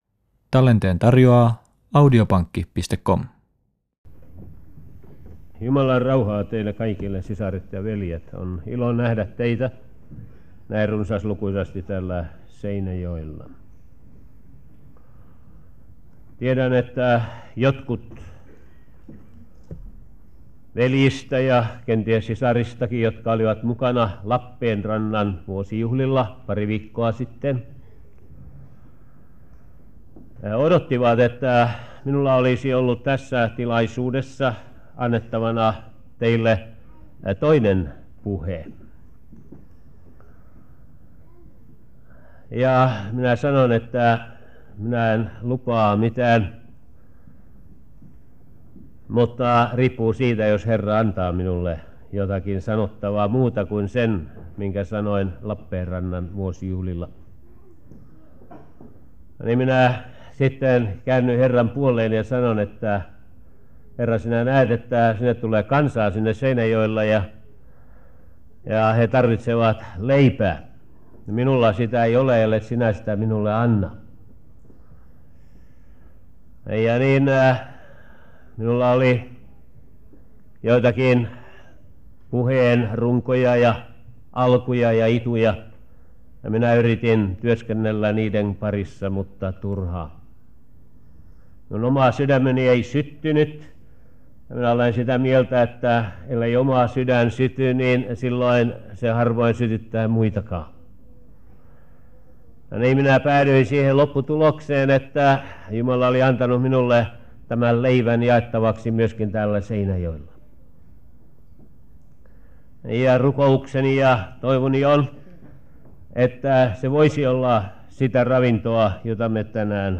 Tämä puhe pidettiin aiemmin Lappeenrannassa 3.6.1978.